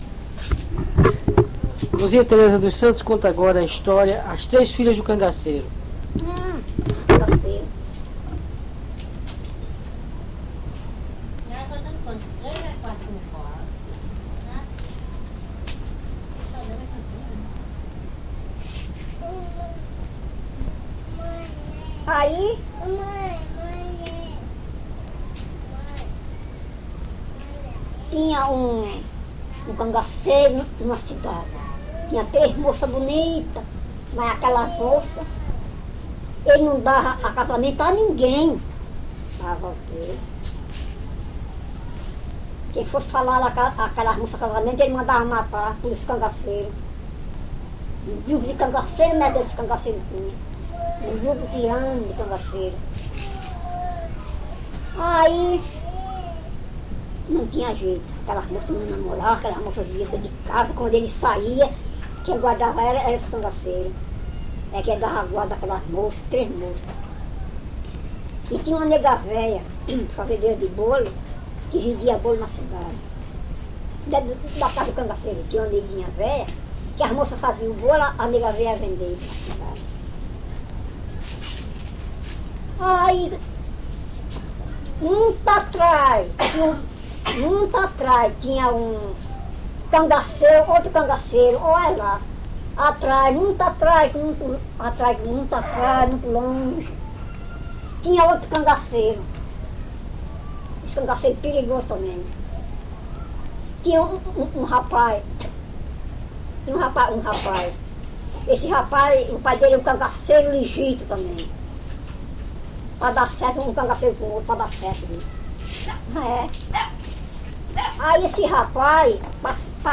Bayeux
AIPV CE7 Lucena 1979 Contadora de Estória.